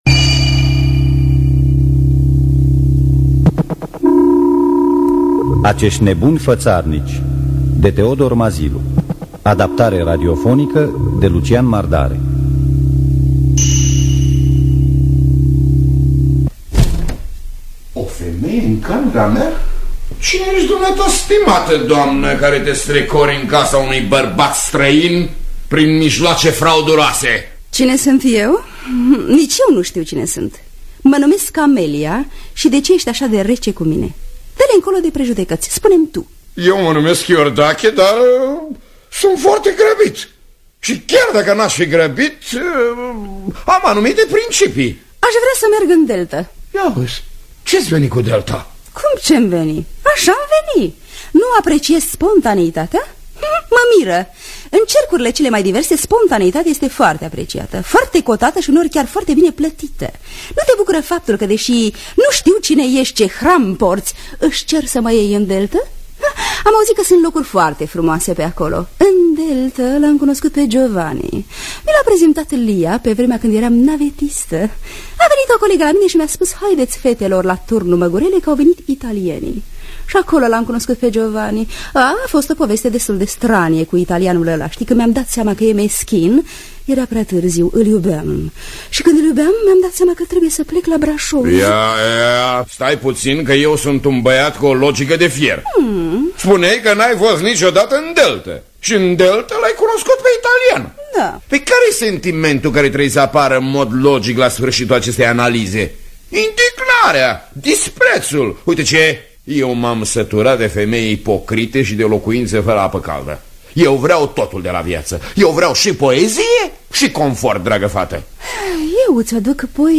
Teodor Mazilu – Acesti Nebuni Fatarnici (1979) – Teatru Radiofonic Online